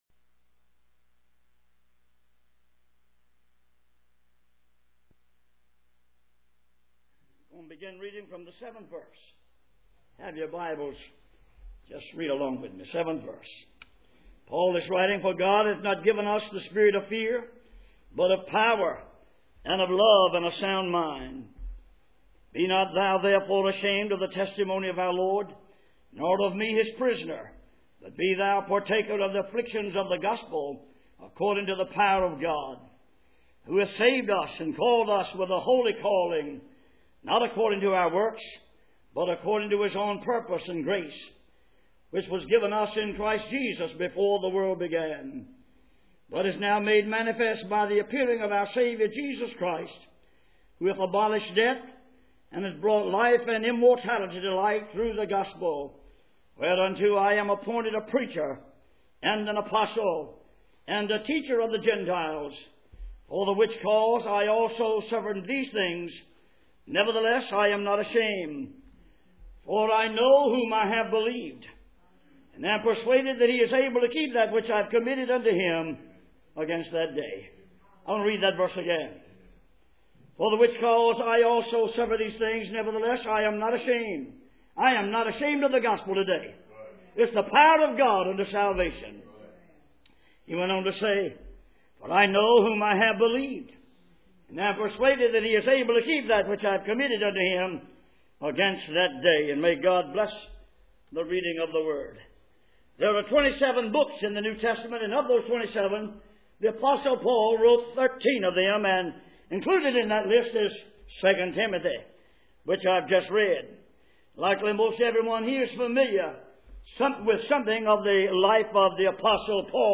Passage: 2 Timothy 1:7-12 Service Type: Sunday Morning